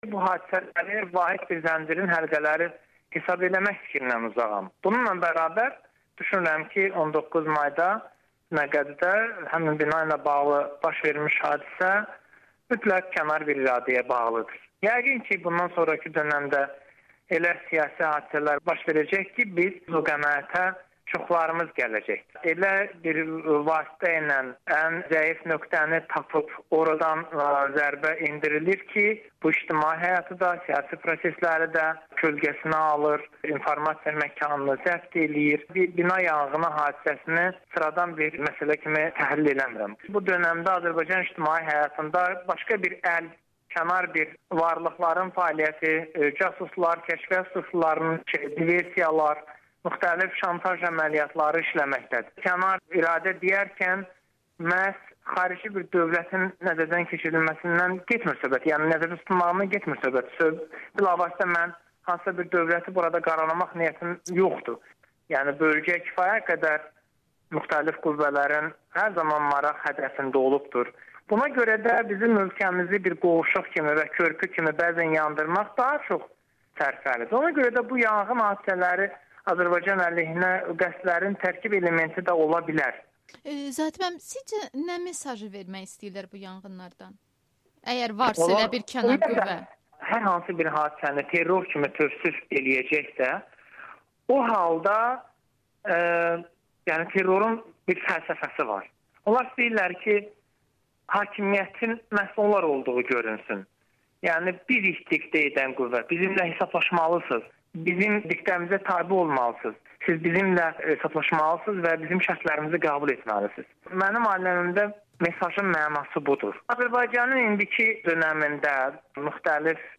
Bununla belə Milli Məclisin Təhlükəsizlik komitəsinin üzvü AzadlıqRadiosuna müsahibəsində deyib ki, o faktlara yox, ehtimallara söykənir.